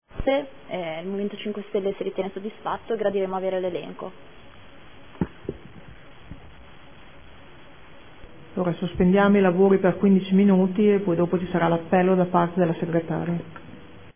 Seduta del 13/11/2014 Replica a risposta Assessore.